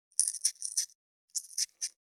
505,桂むき,大根の桂むきの音切る,包丁,厨房,台所,野菜切る,咀嚼音,ナイフ,調理音,
効果音厨房/台所/レストラン/kitchen食材